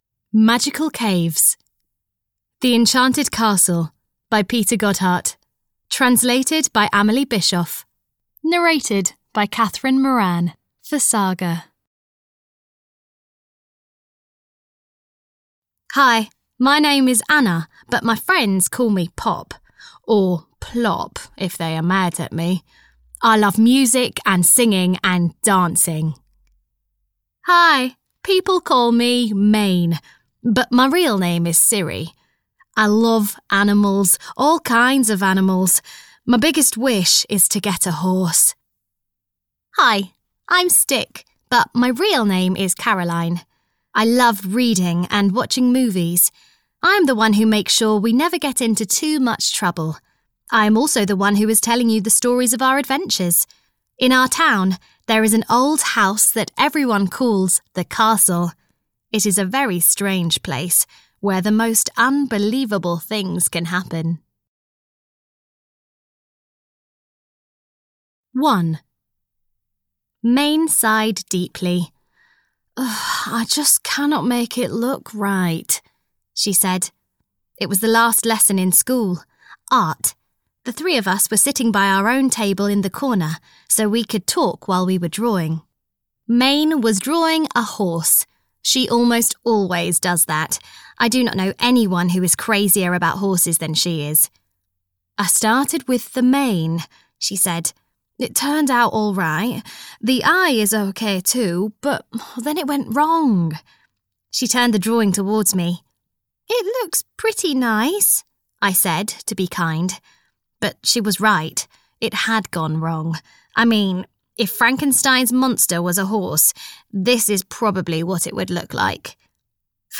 The Enchanted Castle 5 - Magical Caves (EN) audiokniha